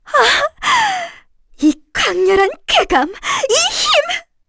Erze-Vox_Victory_kr.wav